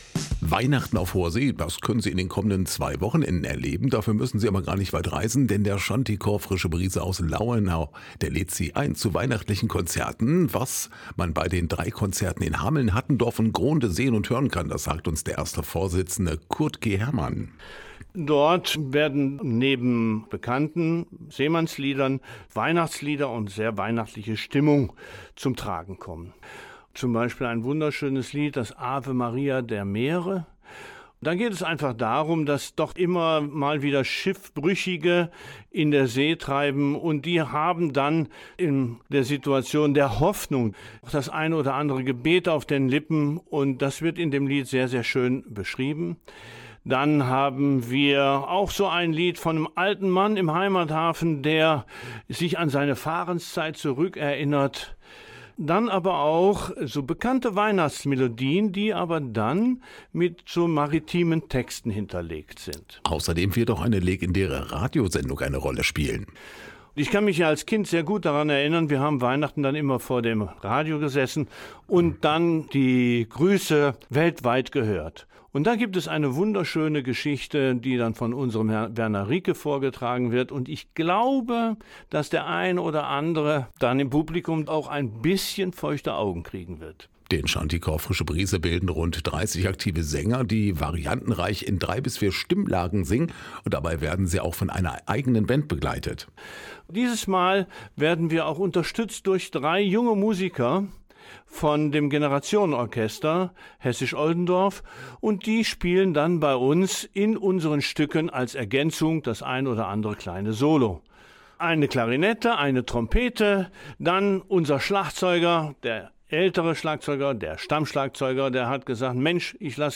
Aktuelle Lokalbeiträge